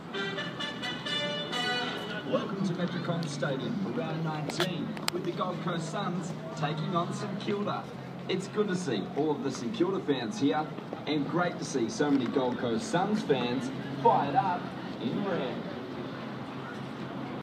Fans are welcomed to the clash between the Suns and Saints at Metricon Stadium on the Gold Coast